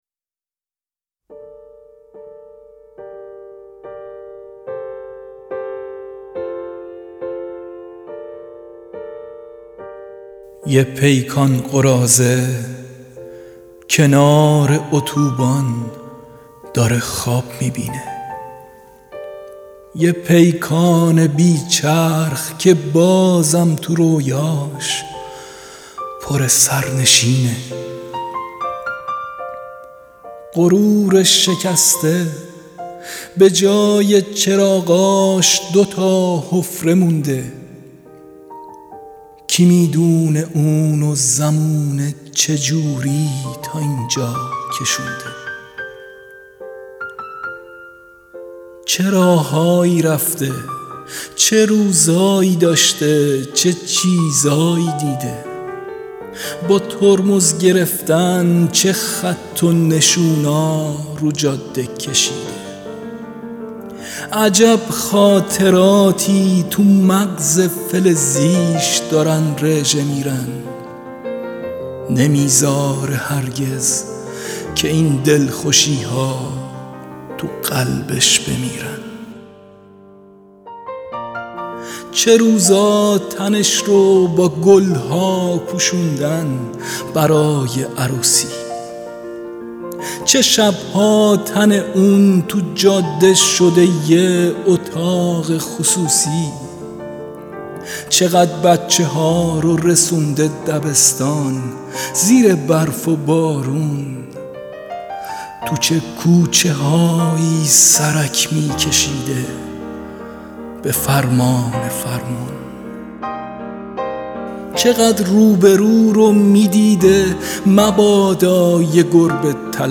دانلود دکلمه پیکان با صدای  یغما گلرویی
گوینده :   [یغما گلرویی]